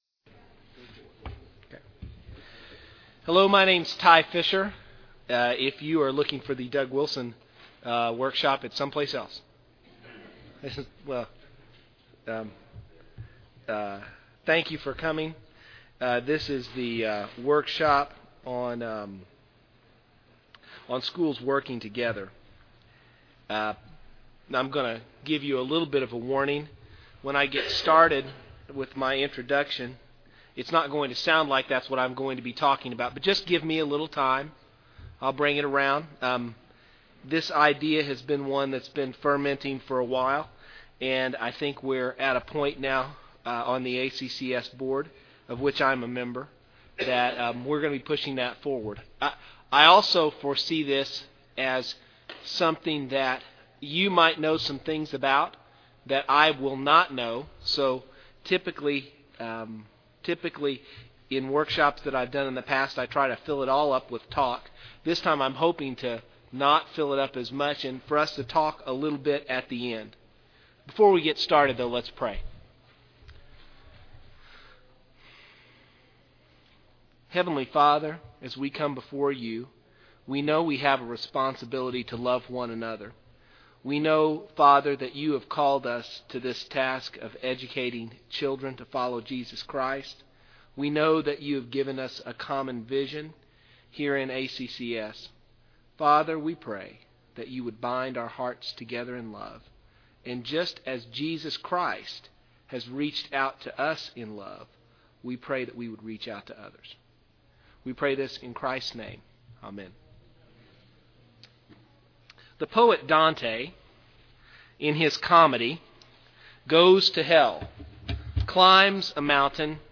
2009 Workshop Talk | 0:55:06 | All Grade Levels, Leadership & Strategic
Mar 11, 2019 | All Grade Levels, Conference Talks, Leadership & Strategic, Library, Media_Audio, Workshop Talk | 0 comments